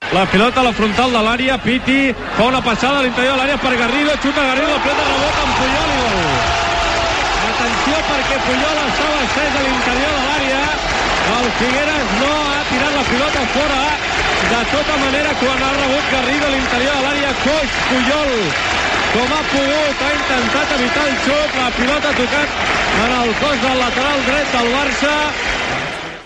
Transmissió dels 32ens de final de la Copa del Rei de futbol masculí entre la Unió Esportiva Figueres i el Futbol Club Barcelona. Inici de la pròrroga i narració del gol del Figueres.
Esportiu